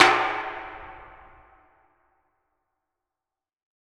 WATERTANKJ.wav